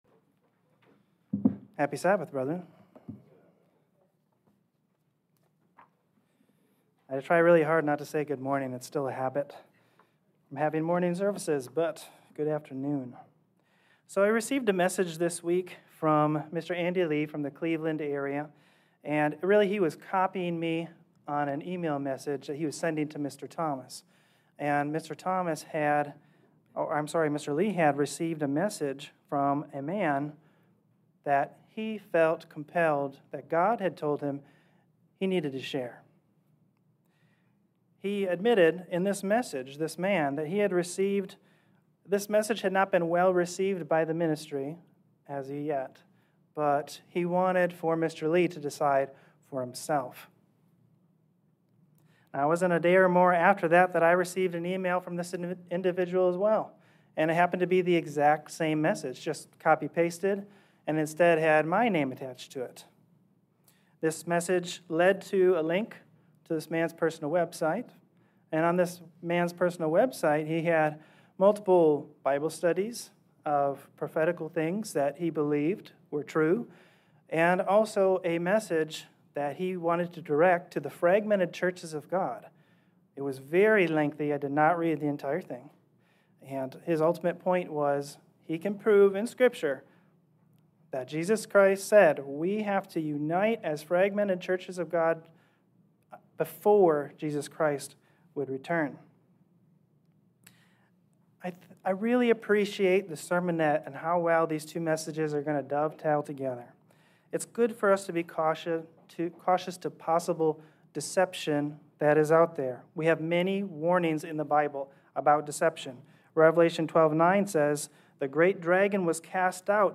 Sermons
Given in North Canton, OH